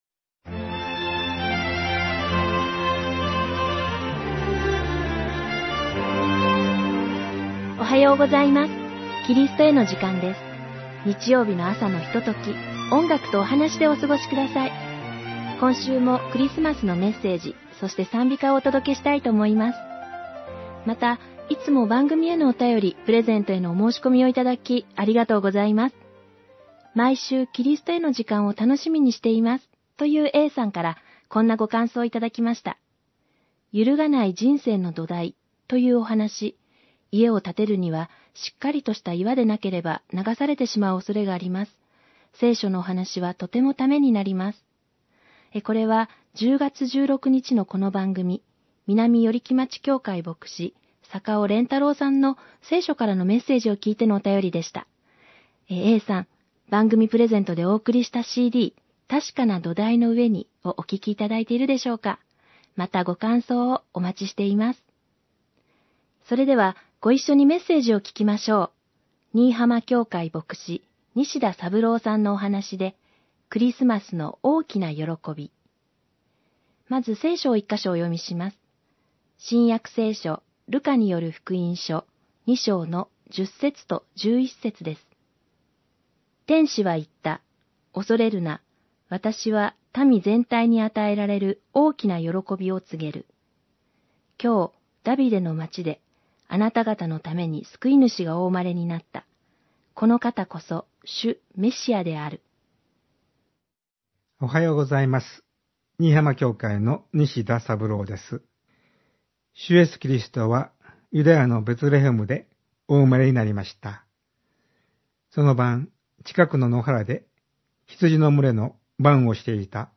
※ホームページでは音楽著作権の関係上、一部をカットして放送しています。